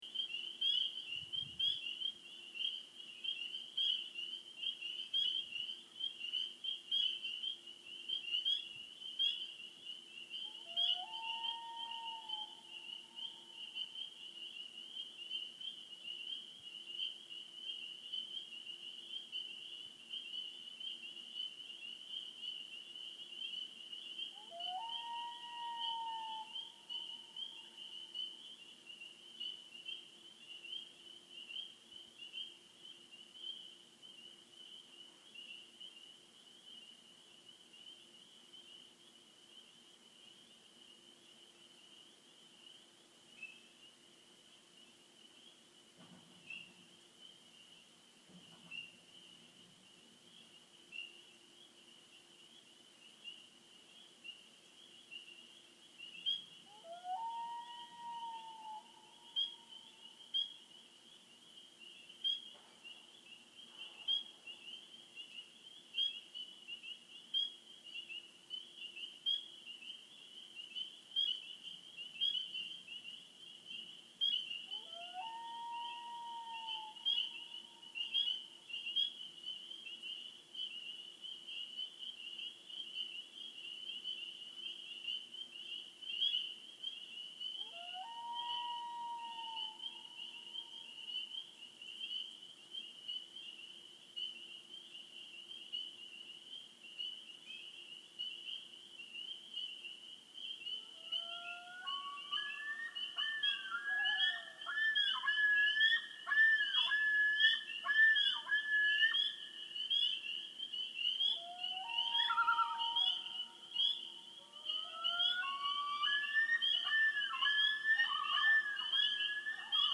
Here are some sounds from a typical night on Sugar Maple.
up-north-sounds.mp3